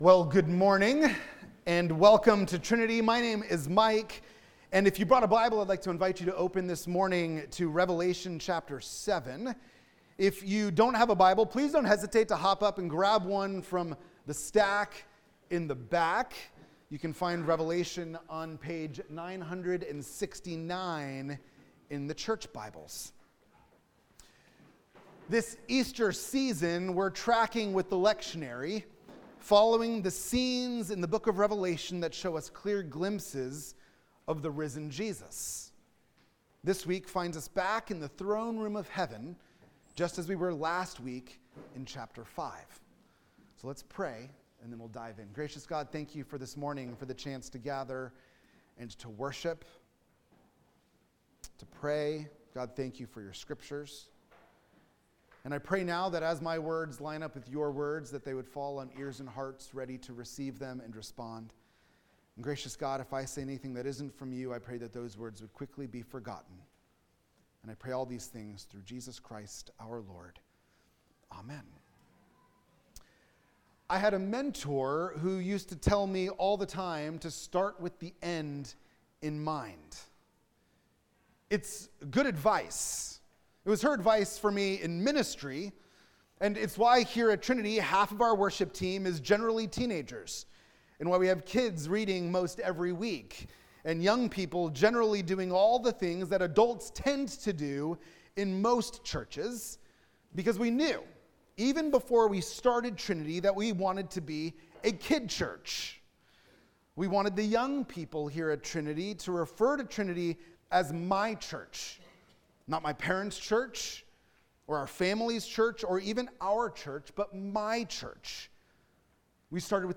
In this Eastertide sermon at Trinity Church, Burke